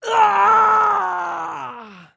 Male_Death_Shout_03.wav